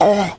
augh.wav